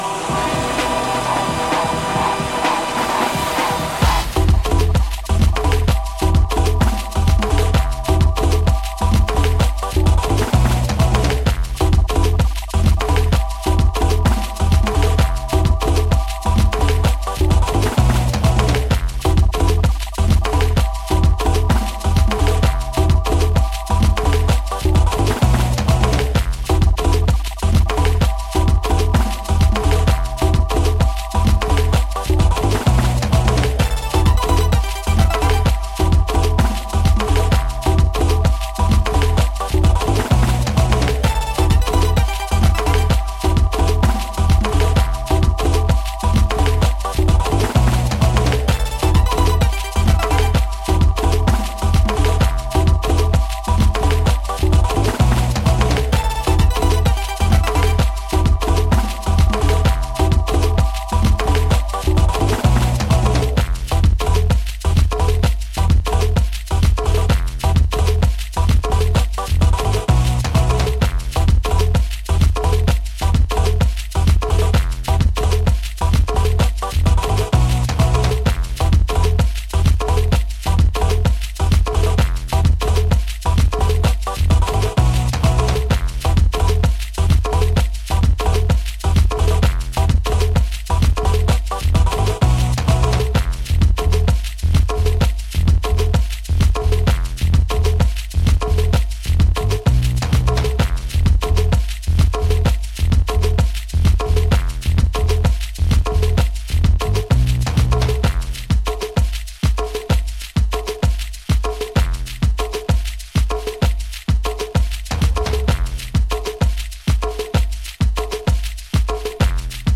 Adventurous fresh and spiritual house tracks